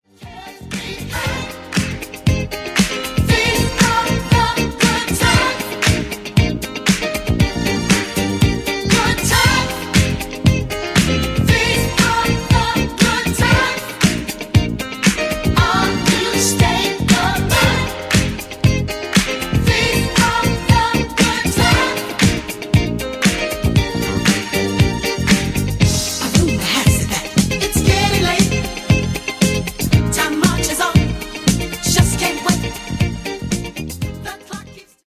Genere:   Disco Funk